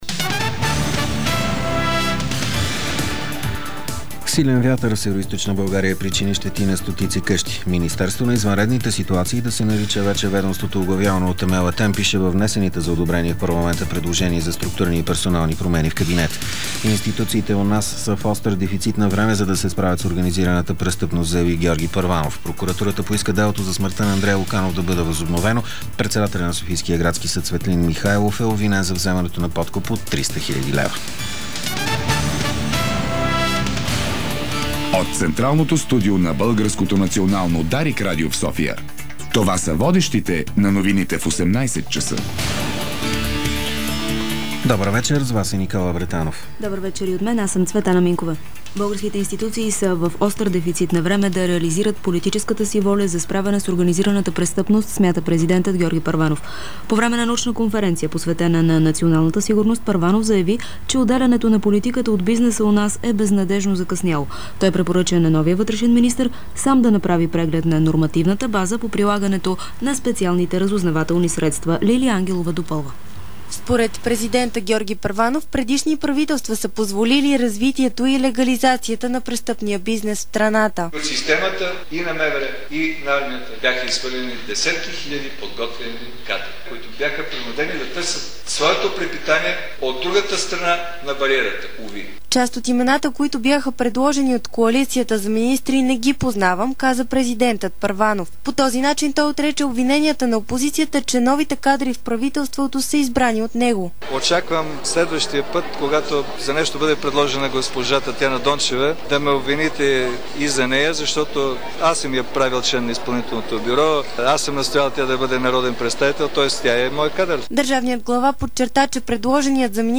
Обзорна информационна емисия - 23.04.2008